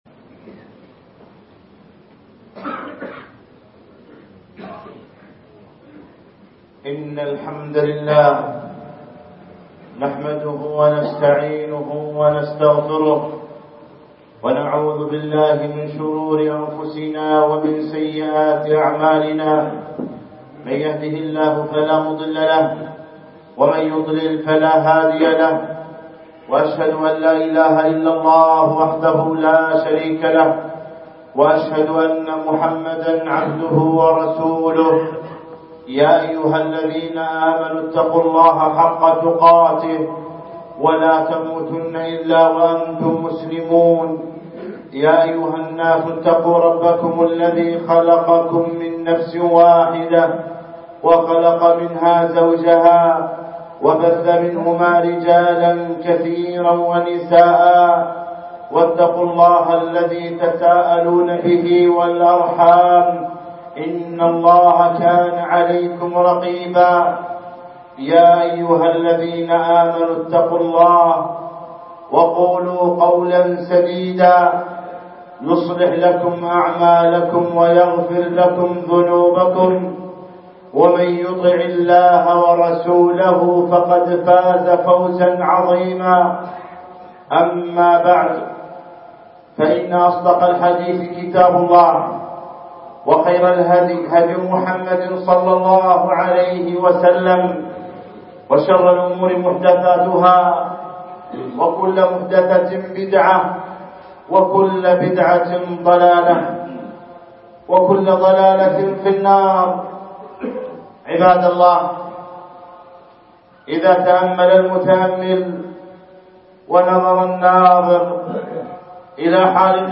خطبة بتاريخ 17 ربيع ثاني 1436 الموافق 6 2 2015